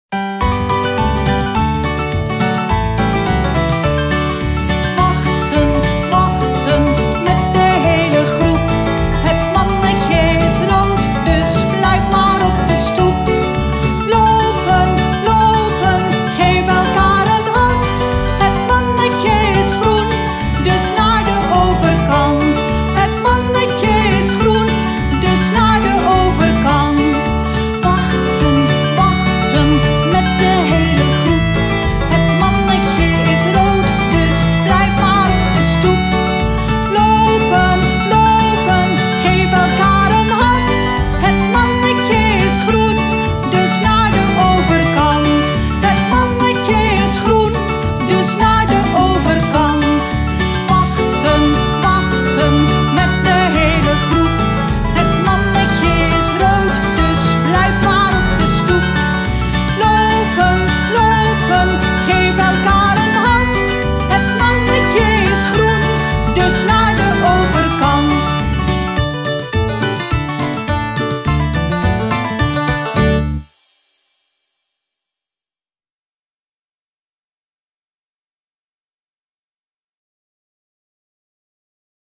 Muziek gezongen